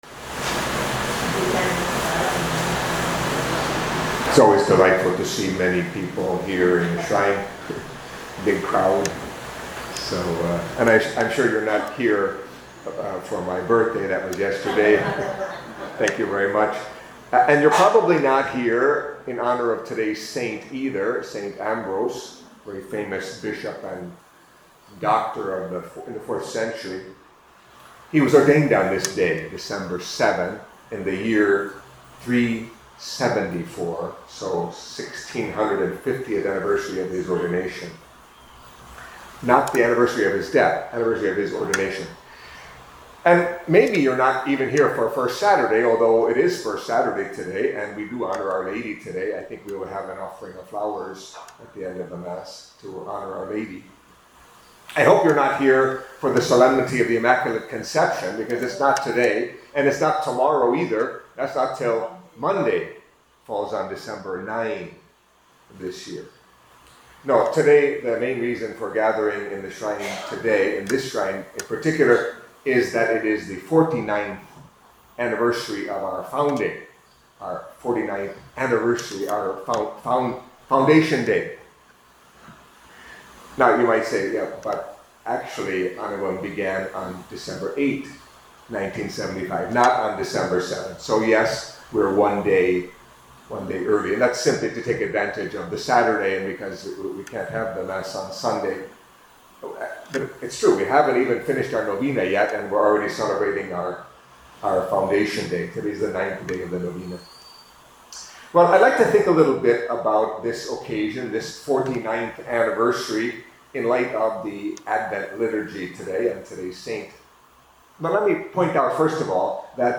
Catholic Mass homily for Saturday of the First Week of Advent